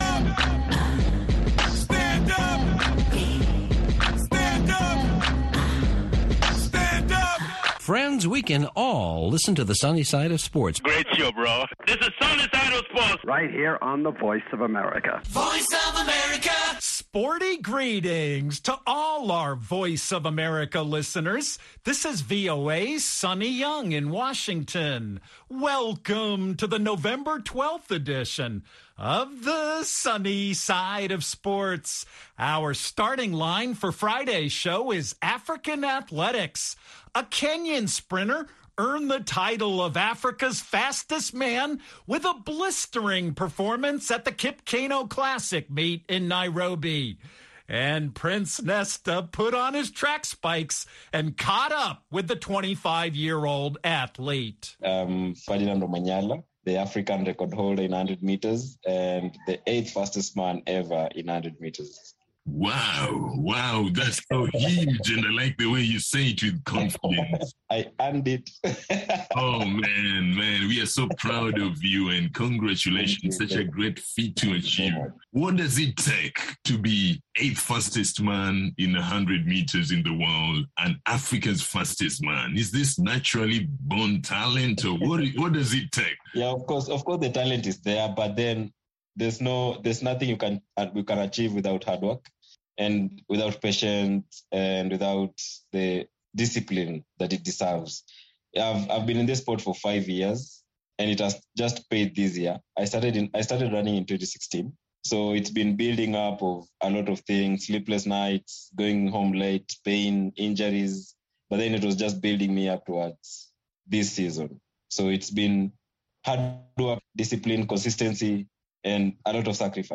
a wide-ranging interview about his career, this year's Tokyo Olympics, family, education, and much more